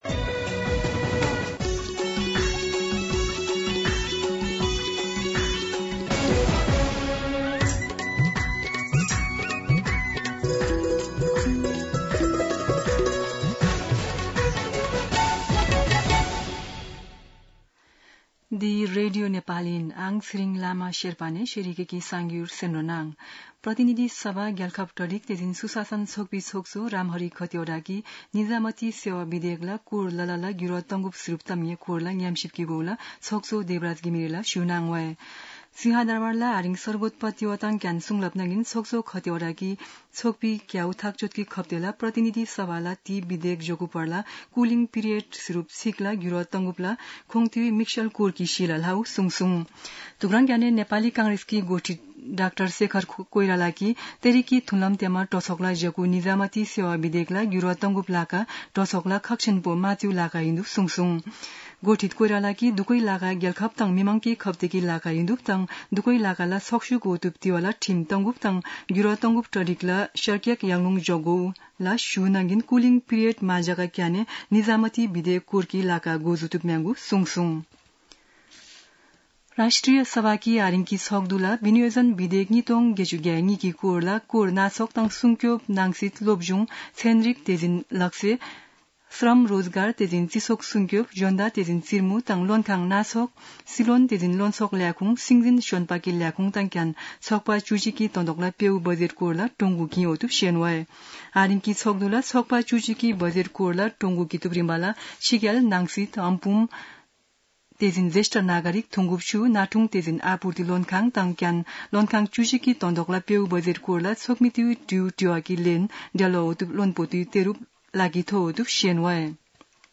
शेर्पा भाषाको समाचार : १७ असार , २०८२
Sherpa-News-03-17.mp3